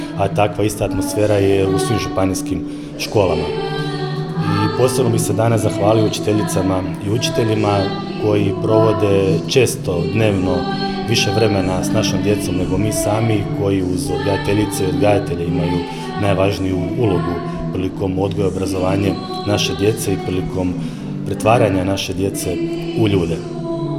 Osjeti se sjajna atmosfera u ovom kolektivu, a to potvrđuju i rezultati koje ostvaruju učenici škole, rekao je danas, nakon prigodnog programa koji su upriličili učenici OŠ Zorke Sever, župan Sisačko-moslavačke županije Ivan Celjak